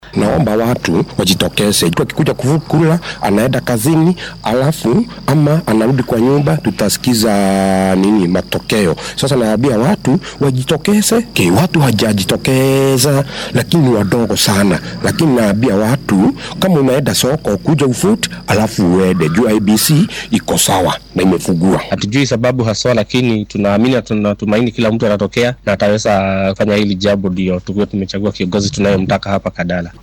Qaar ka mid ah shacabka Kandara ayaa dareenkooda la wadaagay warbaahinta.